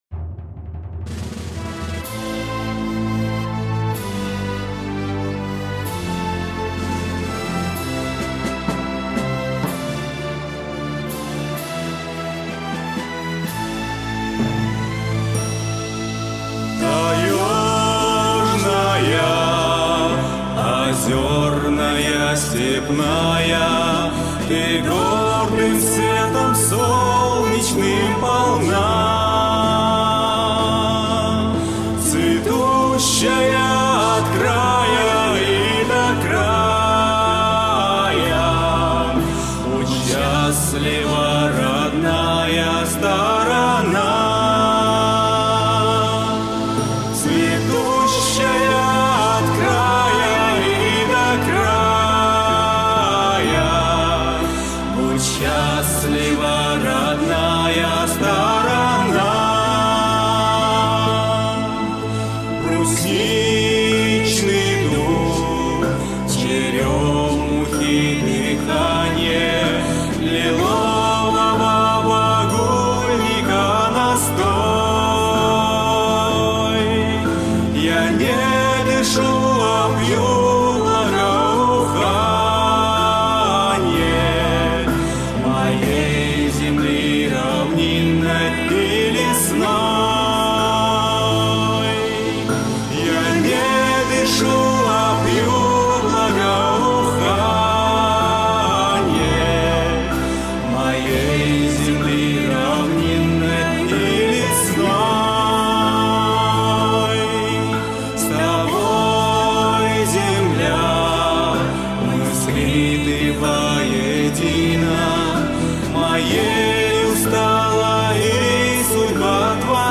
Музыка